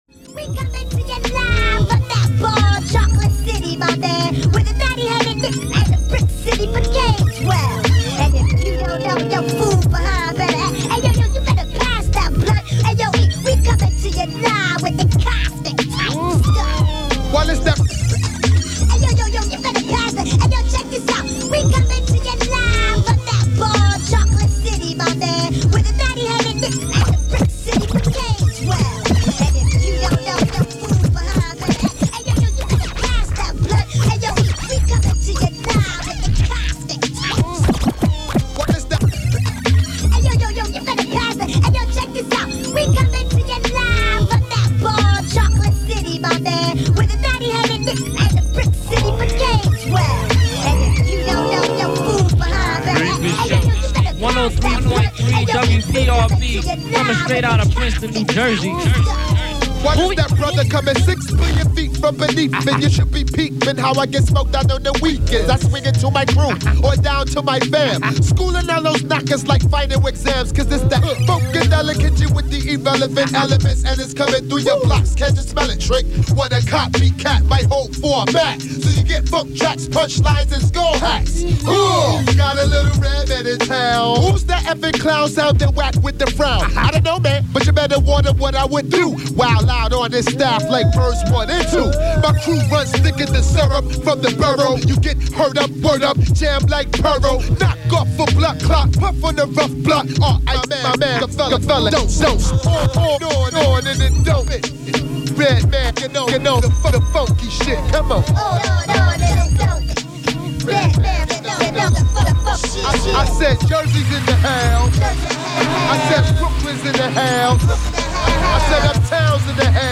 This is one of the very few complete 3-hour shows I found in the box of dusty tapes. It’s got a bit of everything.
Shout outs all over the place and classic hip-hop from 1994 (the golden age).